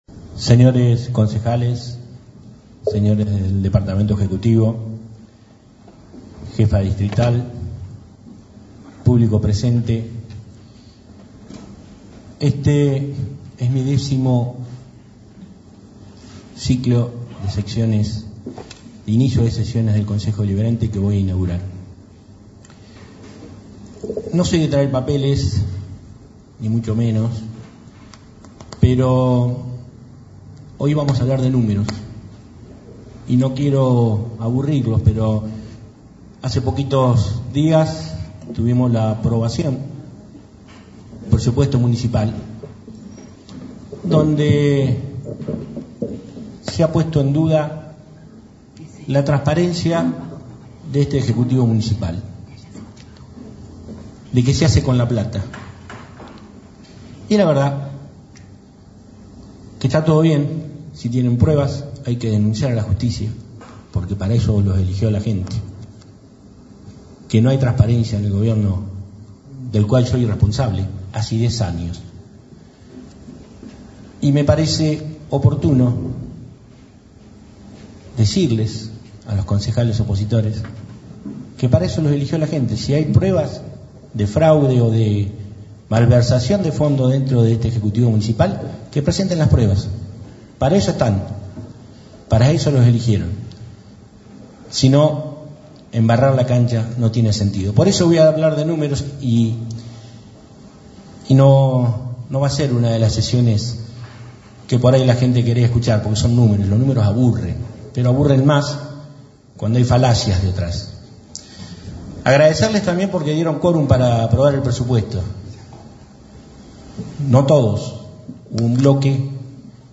Palabras del intendente Municipal en la apertura de Sesiones Ordinarias del Honorable Concejo Deliberante.
Este miércoles 5 de marzo, el intendente Sergio Bordoni encabezó la apertura de las sesiones ordinarias del Honorable Concejo Deliberante, marcando el inicio de su décimo período al frente de este acto institucional.